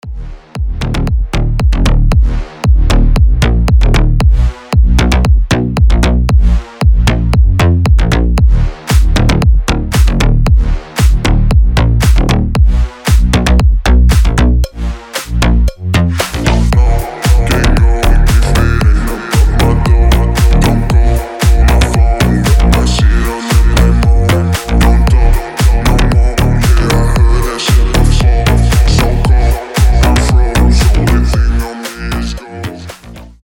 • Качество: 320, Stereo
громкие
жесткие
мощные басы
Bass House
Brazilian bass
качающие
G-House
Шикарный басистый звонок для вас